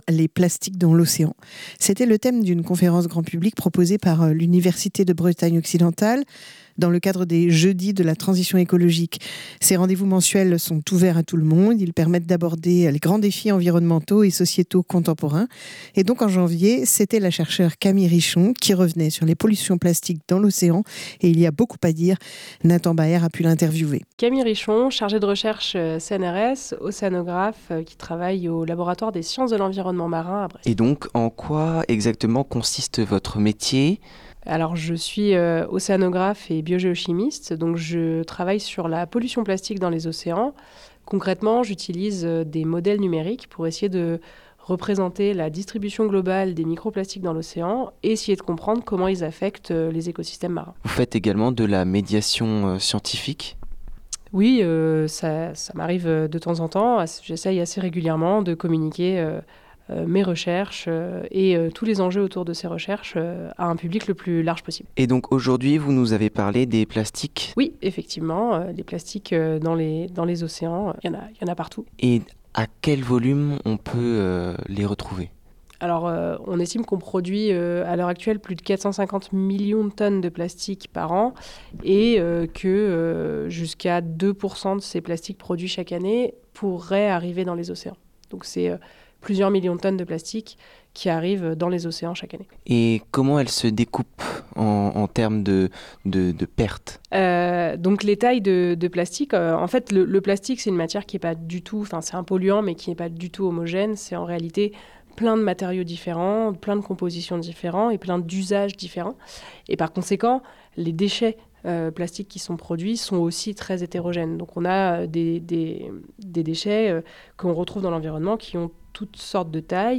Une conférence organisée dans le cadre des Jeudis de la transition écologique, conférences tout public proposées par l'Université de Bretagne occidentale